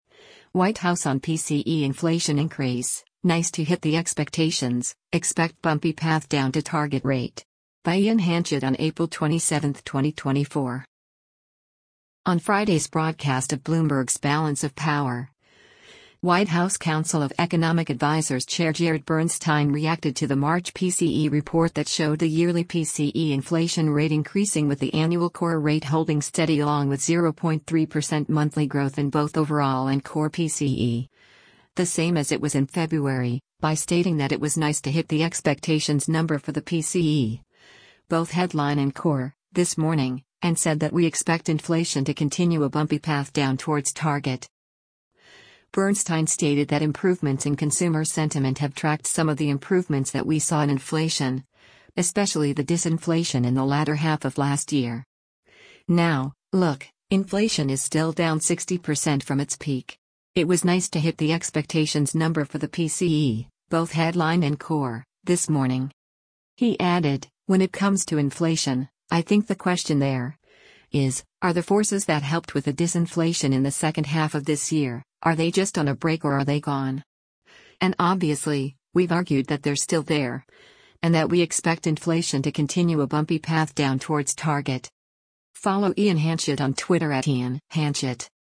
On Friday’s broadcast of Bloomberg’s “Balance of Power,” White House Council of Economic Advisers Chair Jared Bernstein reacted to the March PCE report that showed the yearly PCE inflation rate increasing with the annual core rate holding steady along with 0.3% monthly growth in both overall and core PCE, the same as it was in February, by stating that “It was nice to hit the expectations number for the PCE, both headline and core, this morning.” And said that “we expect inflation to continue a bumpy path down towards target.”